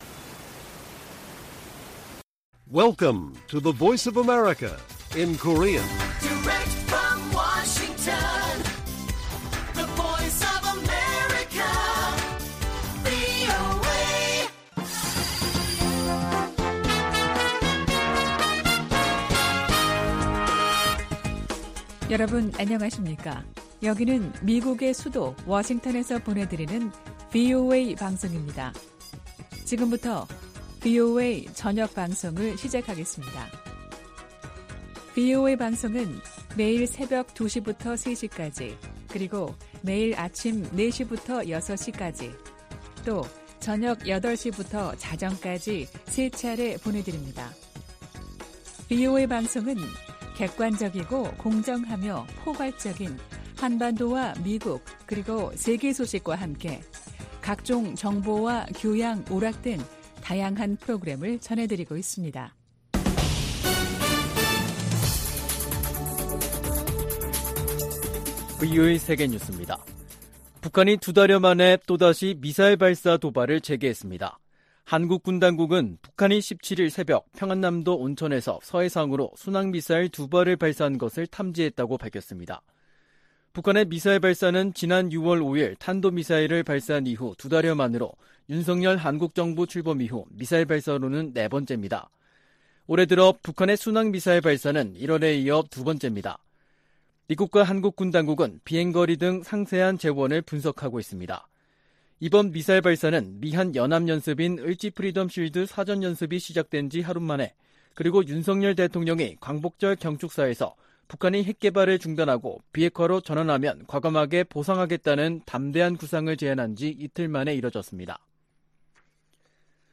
VOA 한국어 간판 뉴스 프로그램 '뉴스 투데이', 2022년 8월 17일 1부 방송입니다. 북한이 두 달여 만에 미사일 발사 도발을 재개했습니다. 윤석열 한국 대통령은 광복절 경축사에서 밝힌 담대한 구상 대북 제안은 북한이 비핵화 의지만 보여주면 적극 돕겠다는 것이라며 북한의 호응을 촉구했습니다. 미 국무부는 미국과 한국의 연합 군사훈련이 한국 안보를 지키기 위한 순수한 방어 목적임을 강조했습니다.